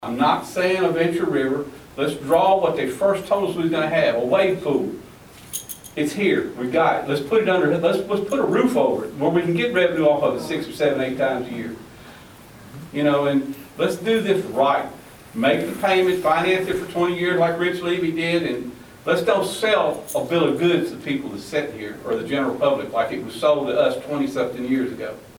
At a town hall Wednesday night, the room may have been full of residents and city officials, but only a handful voiced opinions on Bluegrass Splash, and those were split.